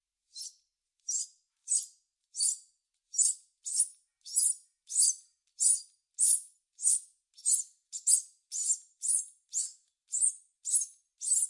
OWI " 啮齿动物的尖叫声
描述：通过在塑料杆上上下摩擦手来创建。